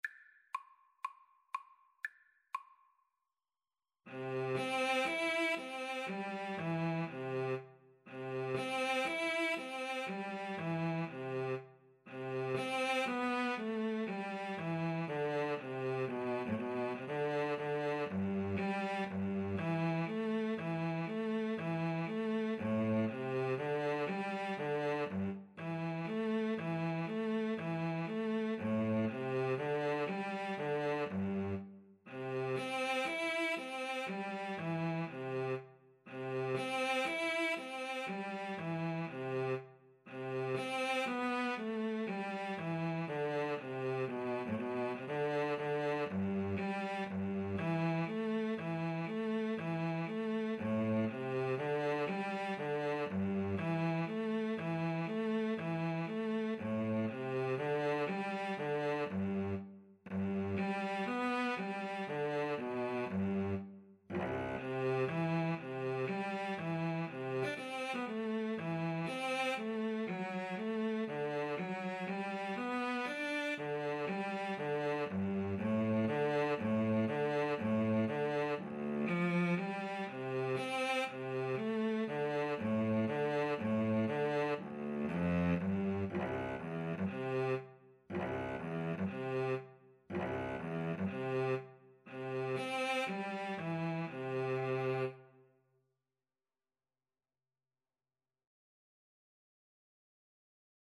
Maestoso =120
Classical (View more Classical Viola-Cello Duet Music)